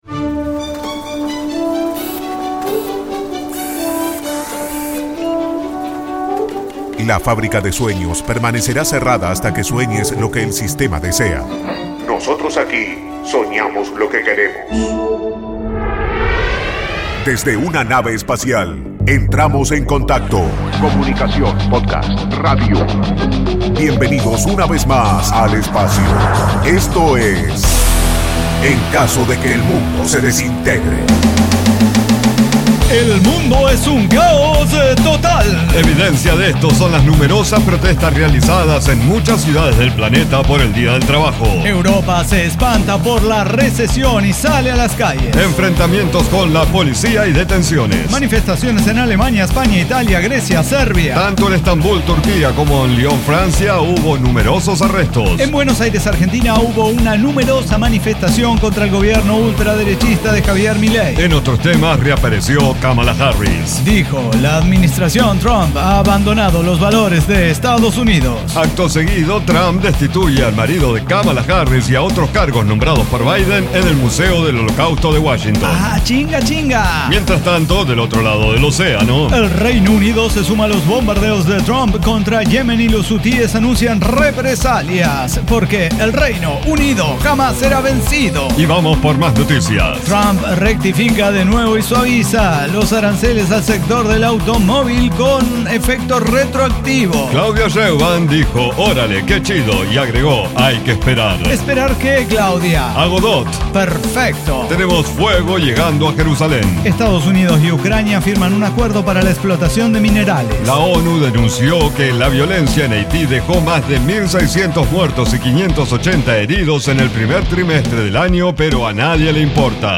ECDQEMSD El Cyber Talk Show - Noticias, la comedia y el drama del devenir diario. Historias personales que se reflejan en un laberinto de espejos de un mundo, que al igual que tú, se desintegra en cada acción para generar nuevas experiencias.
Diseño, guionado, música, edición y voces son de nuestra completa intervención humana.